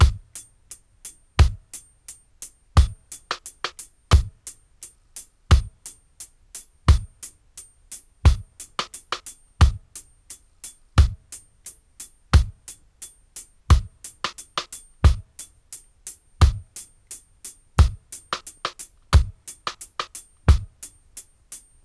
88-DRY-01.wav